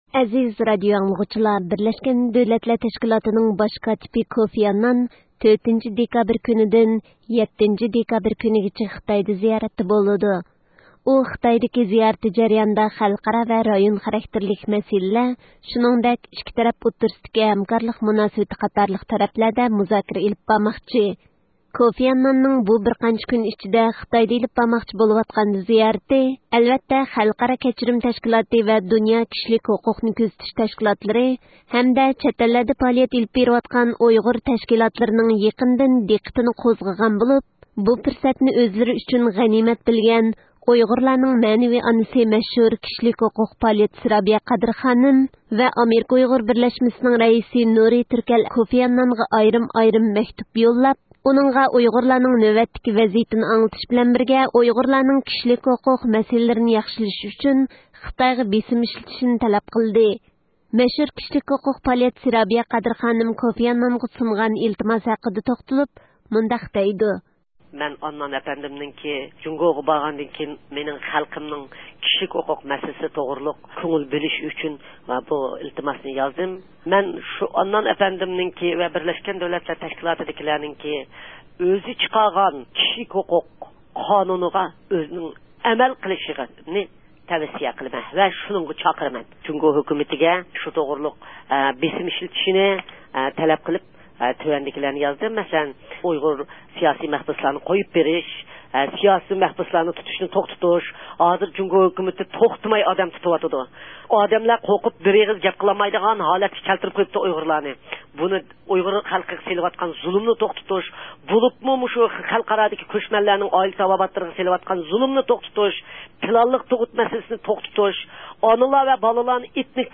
بۇ مۇناسىۋەت بىلەن بىز مەشھۇر كىشىلىك ھوقۇق پائالىيەتچىسى رابىيە قادىر خانىم ۋە ئامېرىكا ئۇيغۇر بىرلەشمىسىنىڭ رەئىسى نۇرى تۈركەل ئەپەندىنى زىيارەت قىلدۇق.